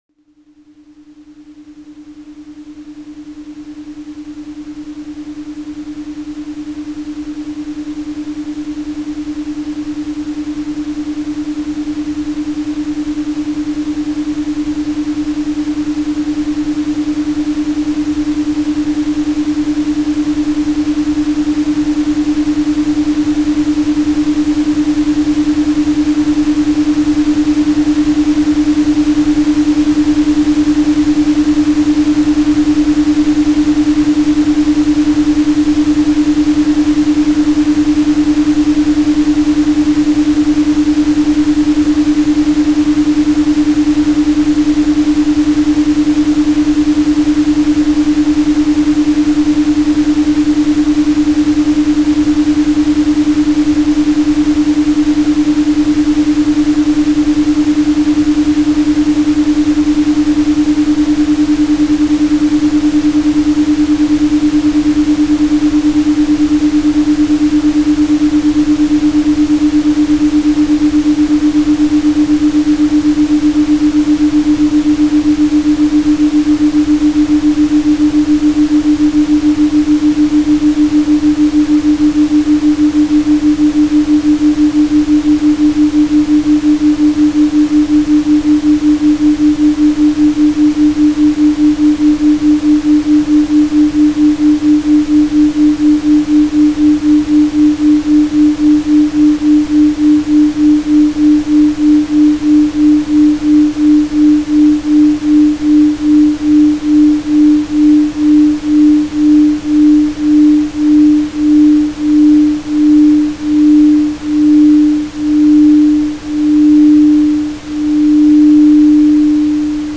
Цифровой наркотик (аудио наркотик) СЧАСТЬЕ